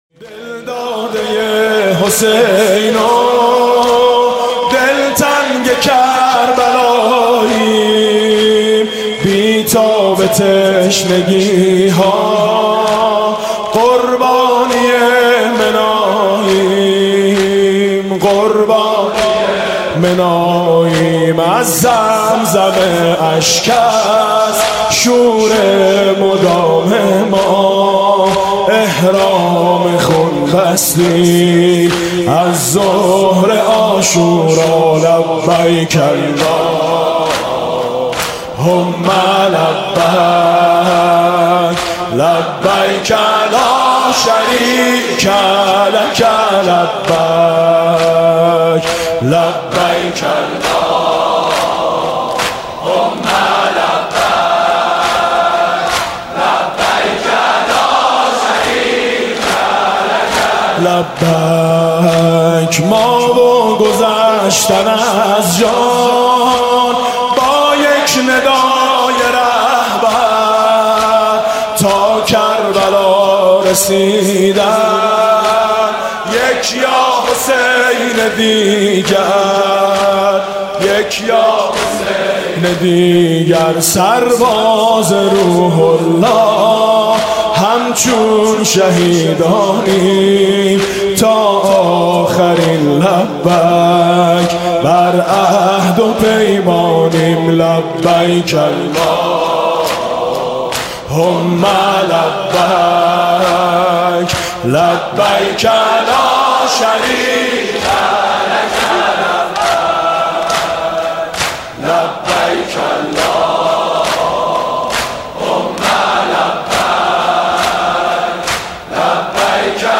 دم پایانی ، سال 94،جدید